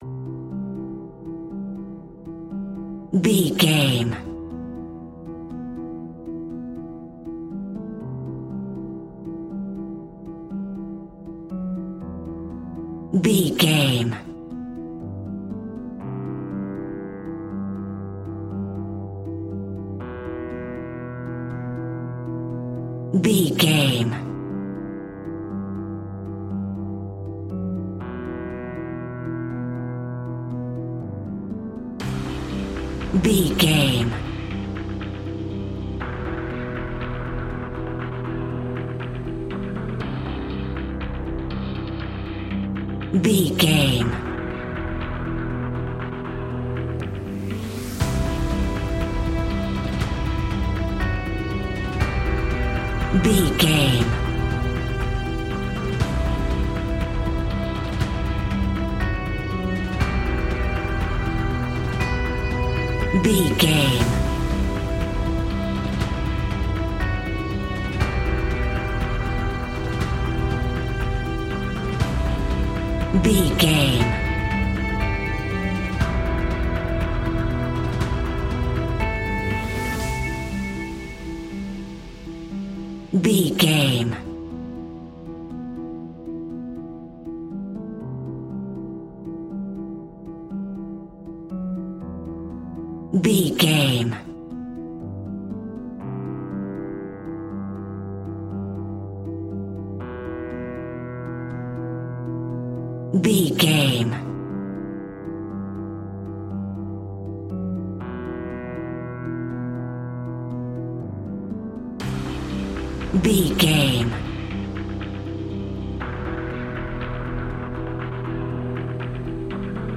Aeolian/Minor
dramatic
intense
epic
synthesiser
drums
strings
electric guitar
suspenseful
creepy
horror music